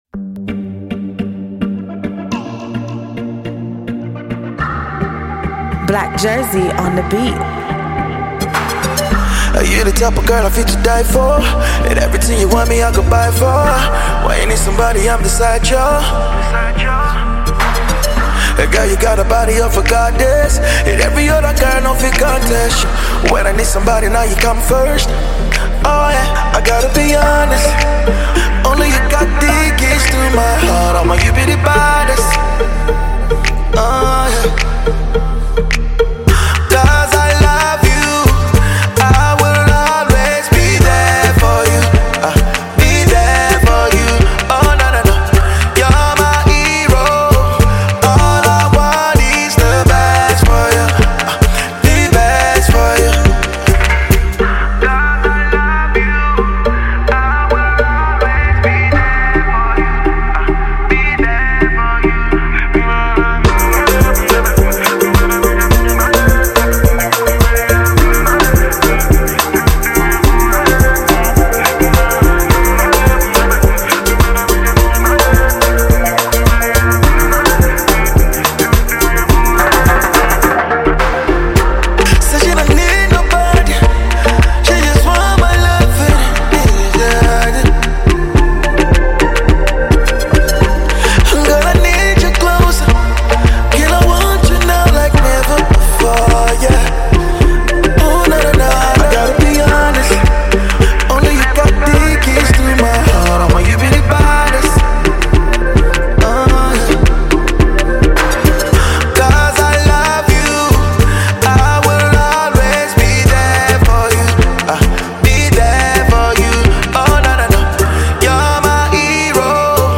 love song
Afro Pop